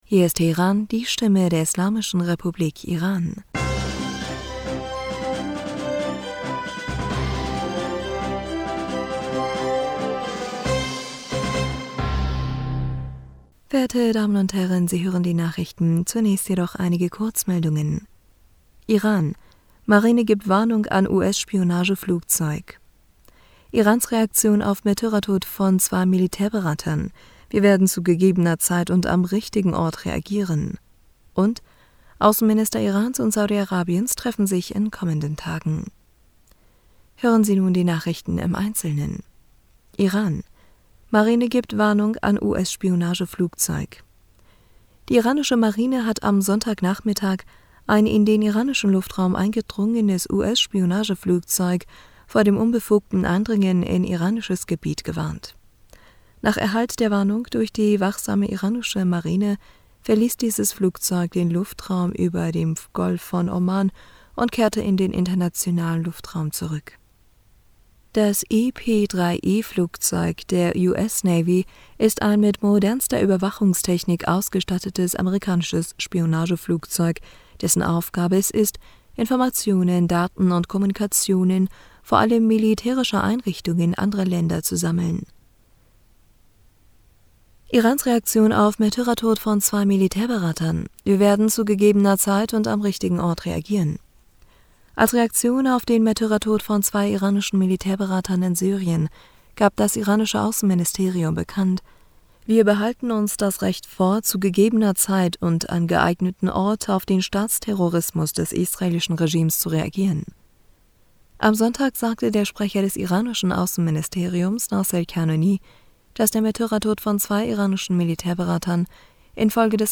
Nachrichten vom 3. April 2023
Die Nachrichten von Montag, dem 3. April 2023